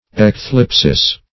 Search Result for " ecthlipsis" : The Collaborative International Dictionary of English v.0.48: Ecthlipsis \Ec*thlip"sis\, n. [L., fr. Gr.